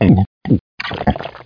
1 channel
drown.mp3